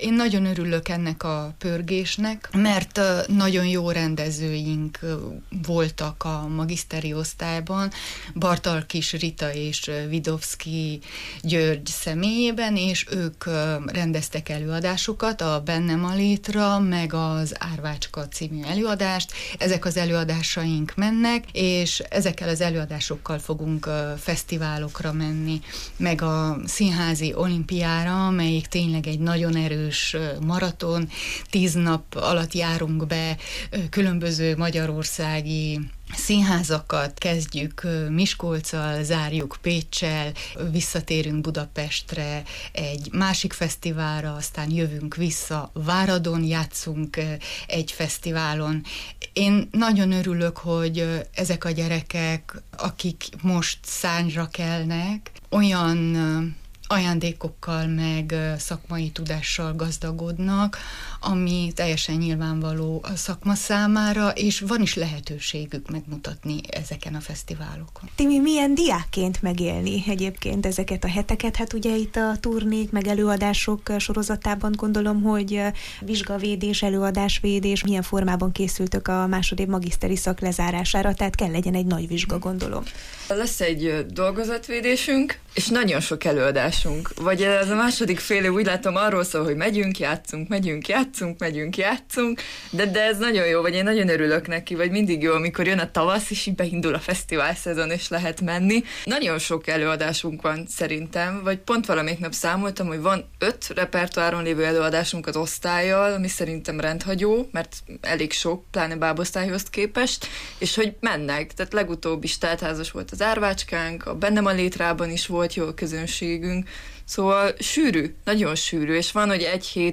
A diákok előadásairól, a bábszínész szak szépségéről, kihívásairól beszélgettünk: